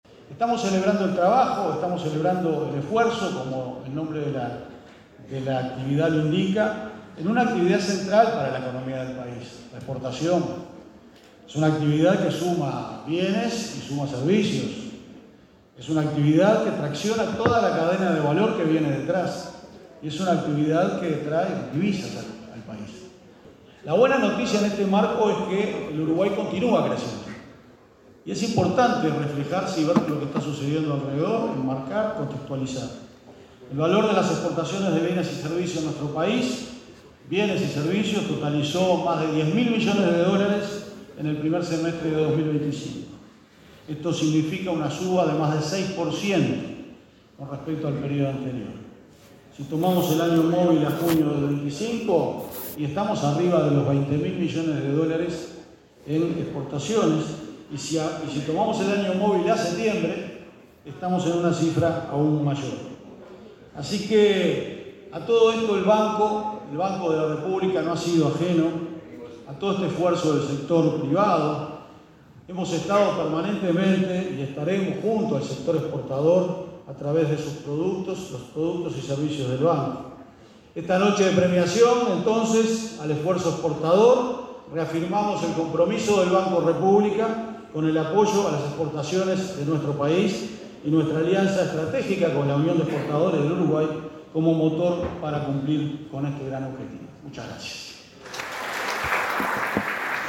El titular del Banco de la República Oriental del Uruguay, Álvaro García, hizo uso de la palabra en la ceremonia de Reconocimiento al Esfuerzo